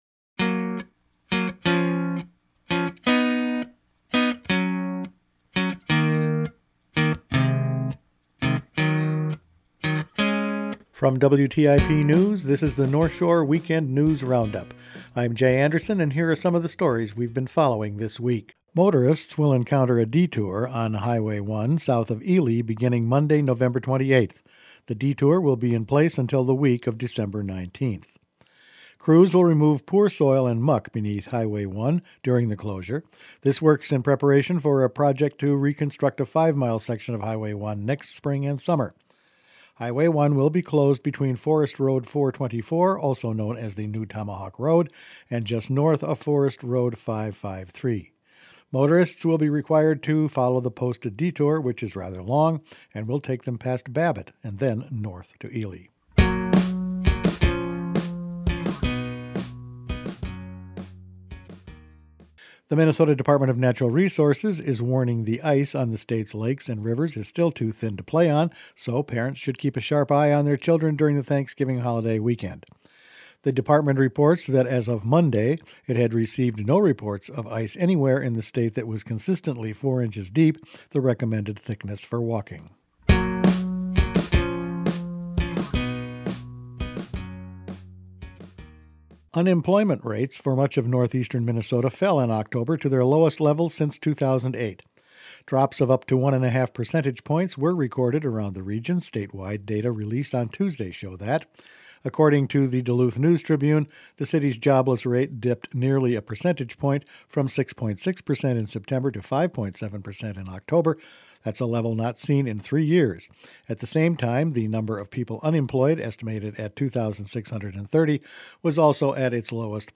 Each weekend WTIP news produces a round up of the news stories they’ve been following this week. A detour on Hwy 1, thin ice, lower unemployment figures, down deer harvest, native art in Thunder Bay and a good year for Christmas trees…all in this week’s news.